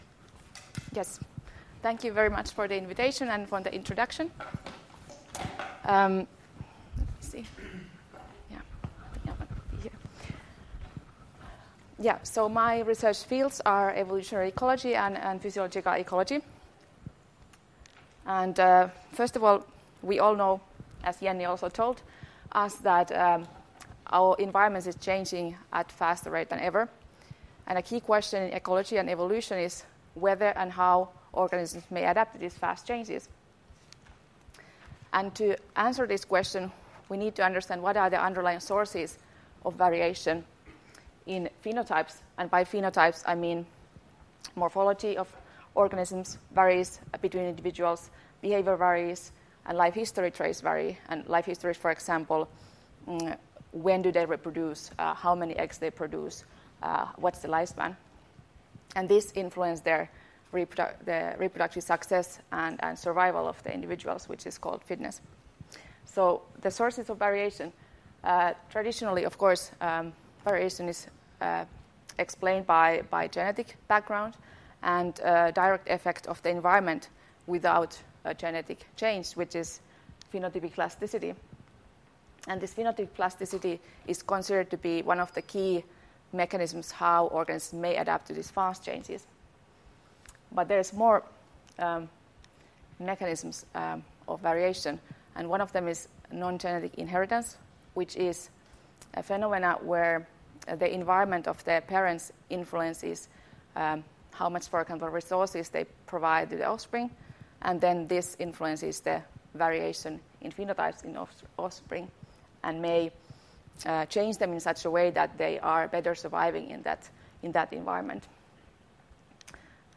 Esitelmäluennot 5.8.2020